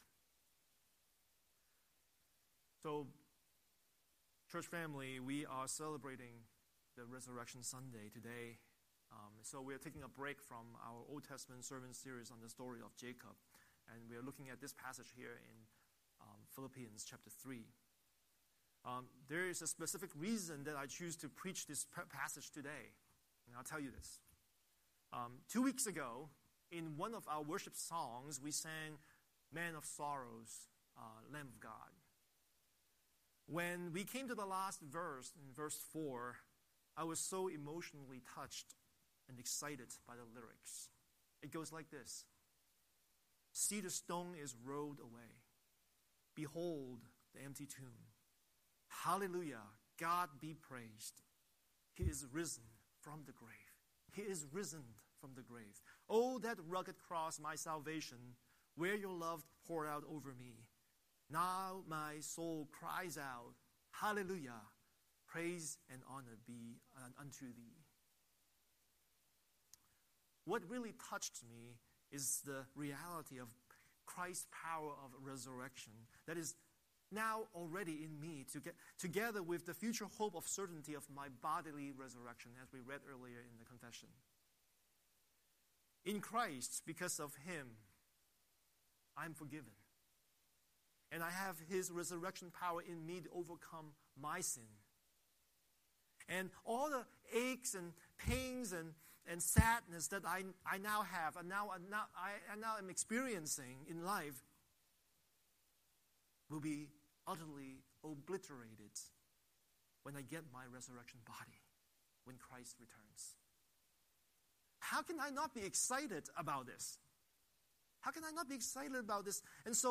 Series: Sunday Sermon